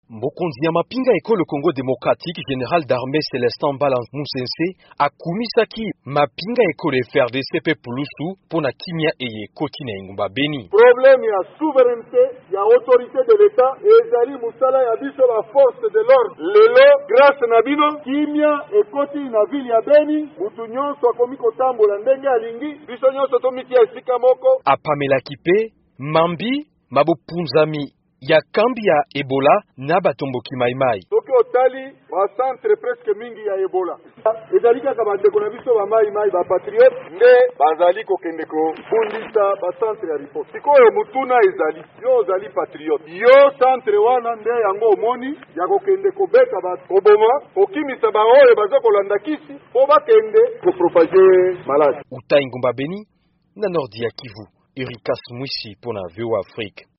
Chef d’Etat-major ya ba FARDC, Général Célestin Mbala Musese alobi ete mampinga mazongisi kimya na Beni mpe apameli ba Maï Maï mpo na kobundisa misala ya Ebola. Aboli yango na lisikulu liboso ya ba soda mpe pulusu na Beni, na Nord-Kivu, esika wapi Etat-major ya mampinga ya RDC etiyami wuto mposo mibale.